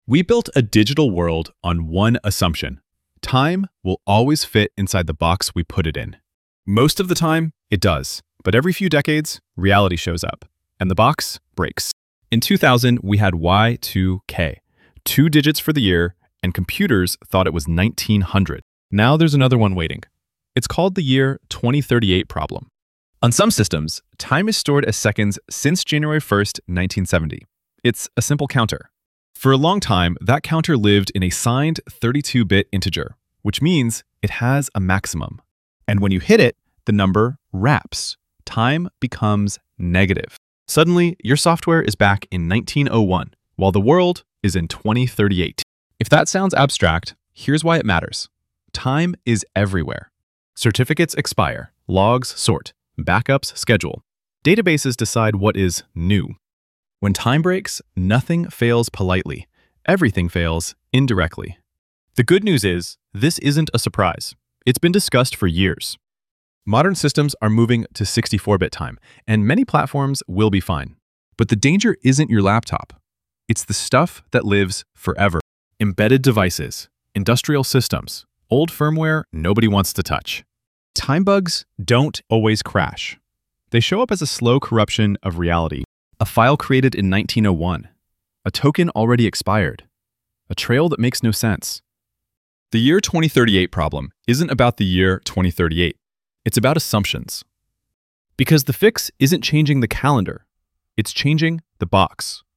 Voiceover-only + script/storyboard.